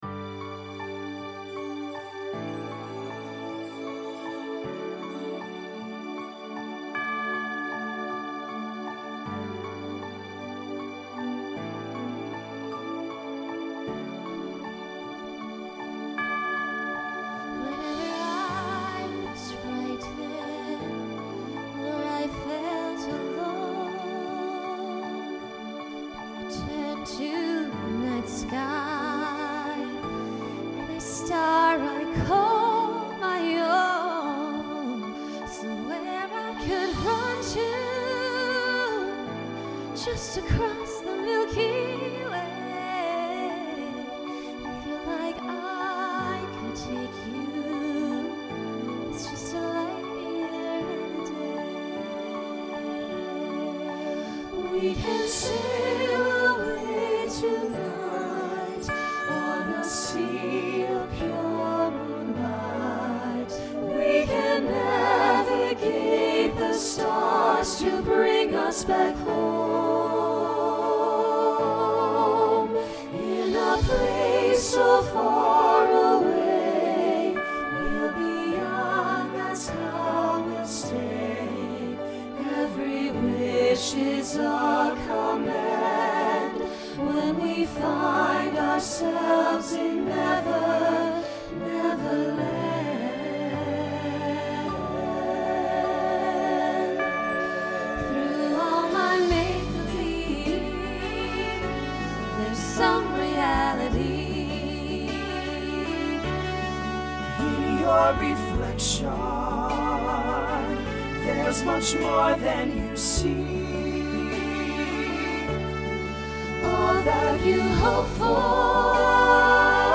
Voicing SATB Instrumental combo Genre Broadway/Film
Function Ballad